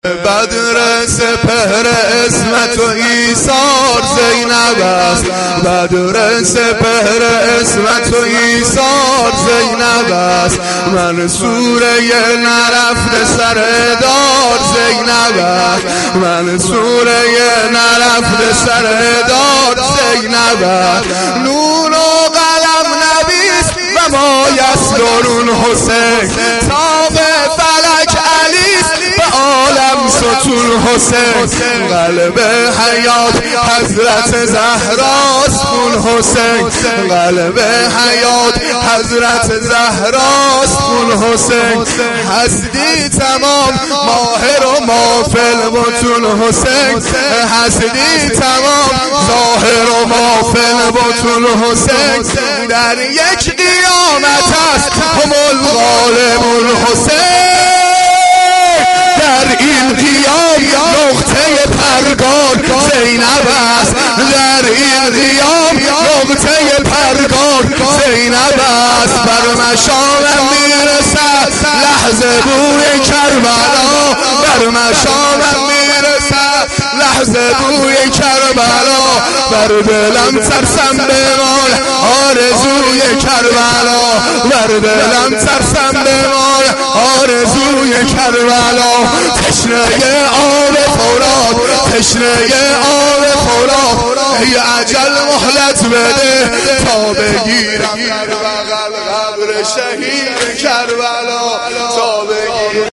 مداحی
Shab-4-Moharam-5.mp3